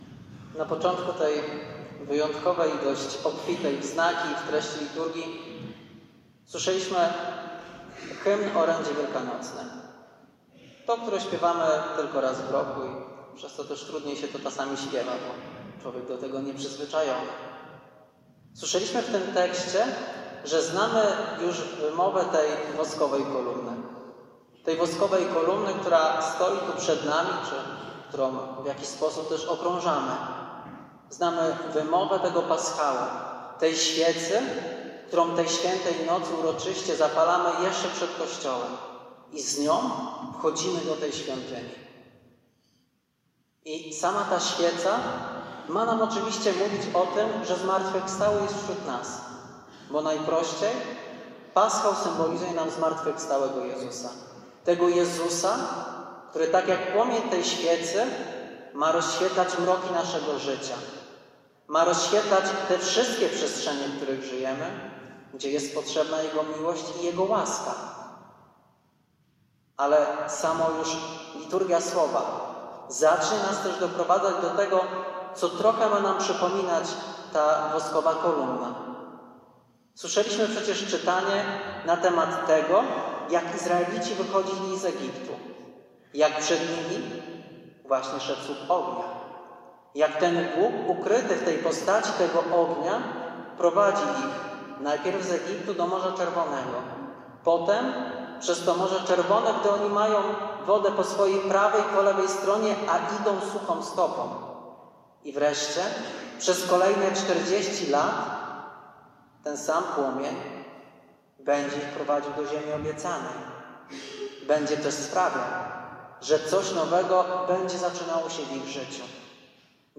homilia-Wigilia-Paschalna-2025.mp3